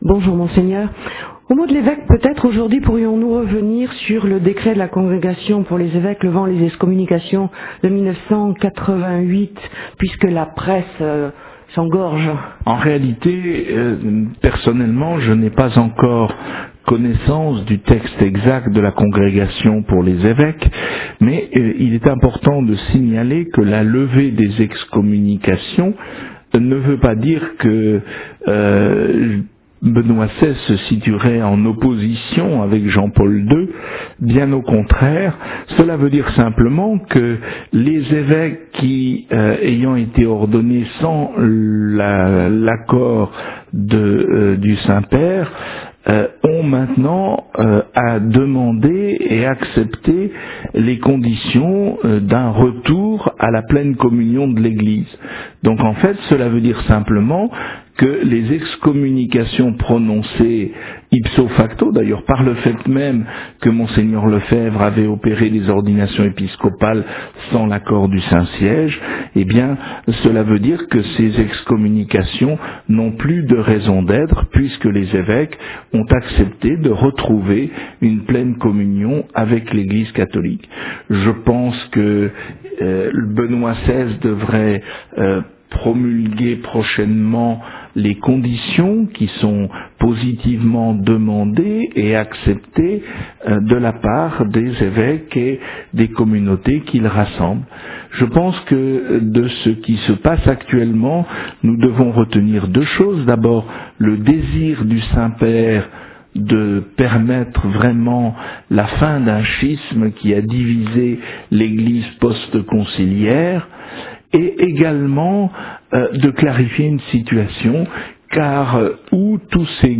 Extrait du « Mot de l’évêque » diffusé le 30 janvier sur RCF Lumières